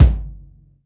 • Smooth Bottom End Steel Kick Drum Sample G Key 737.wav
Royality free kick single hit tuned to the G note. Loudest frequency: 198Hz
smooth-bottom-end-steel-kick-drum-sample-g-key-737-svf.wav